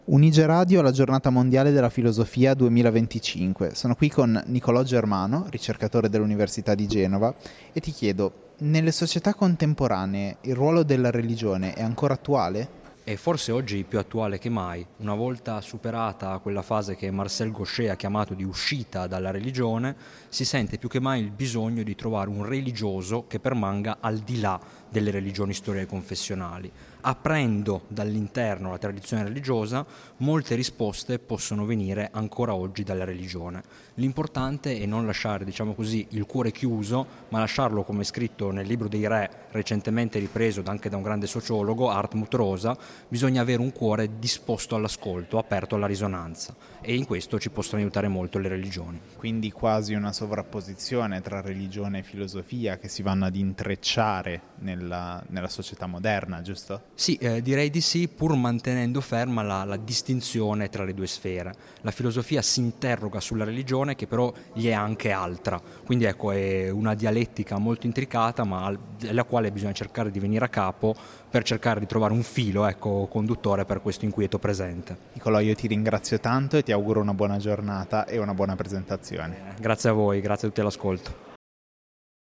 Un dialogo per orientarsi tra fede, pensiero e inquietudine contemporanea.